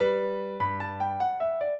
piano
minuet2-4.wav